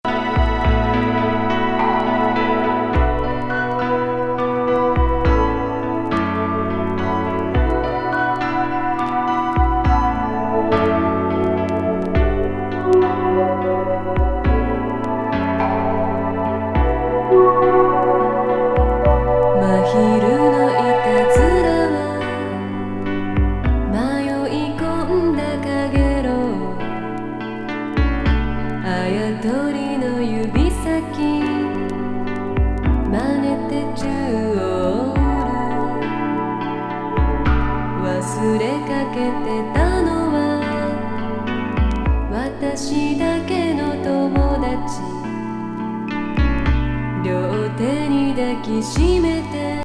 ドリーミー・ニューエイジ・
シンセ・ポップ